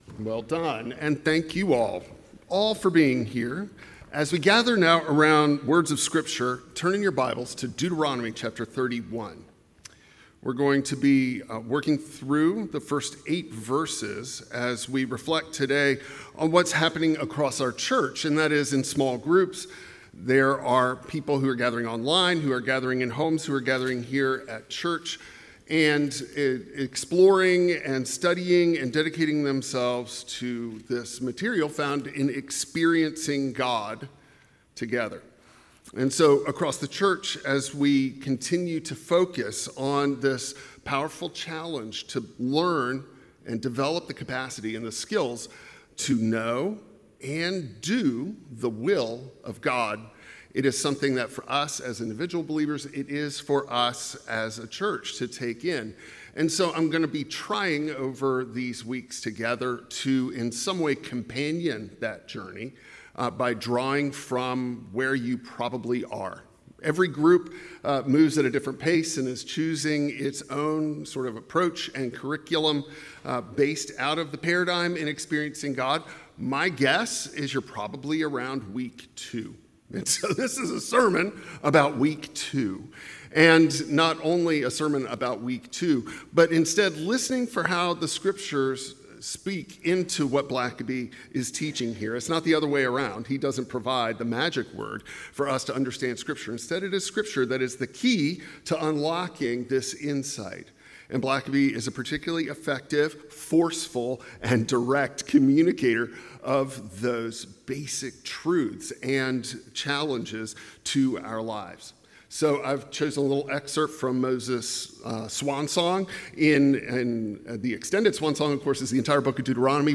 Deuteronomy 31:1-8 Service Type: Traditional Service In transitions and uncertainty